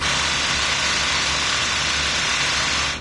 SteamBlat.ogg